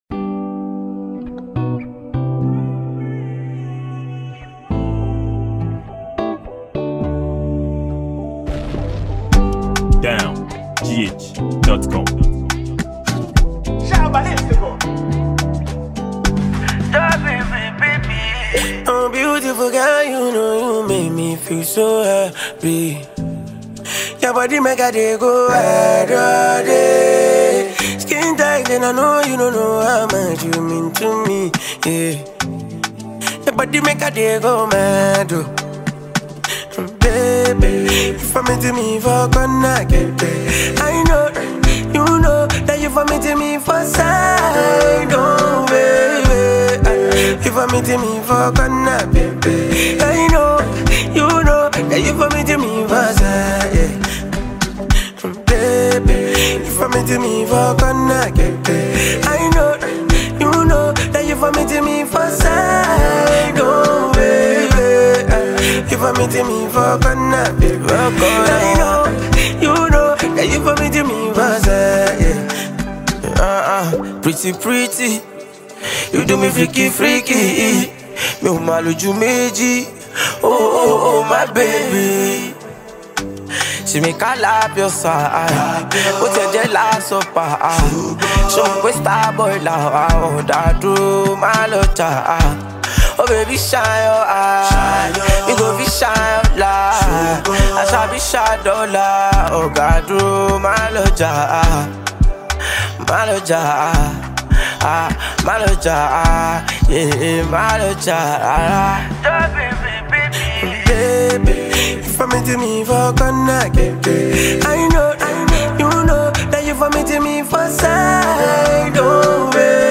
a Ghanaian musician
Nigerian strong and delight singer